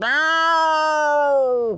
cat_scream_04.wav